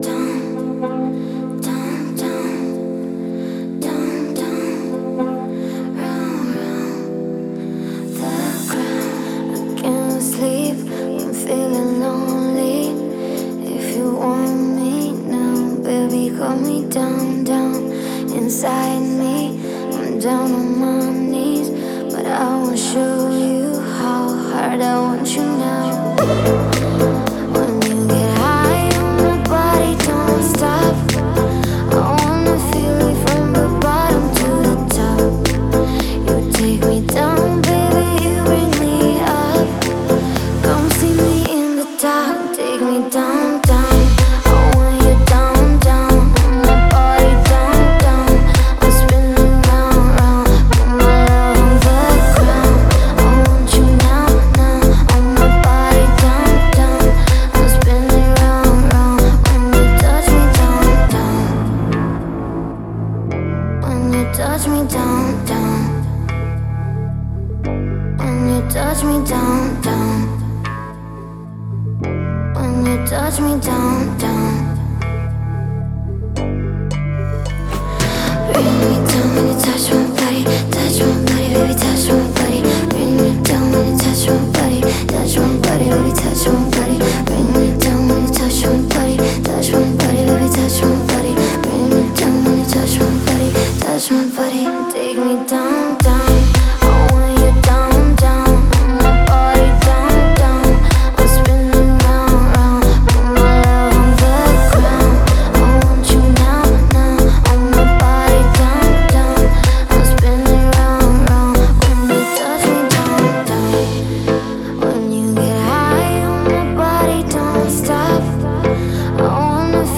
это зажигательная песня в жанре поп с элементами R&B